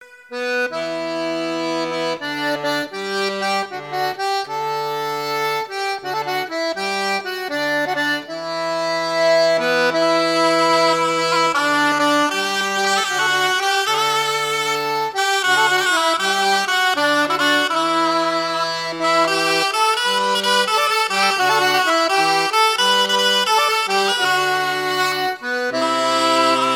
Suite de mélodies
Pièce musicale éditée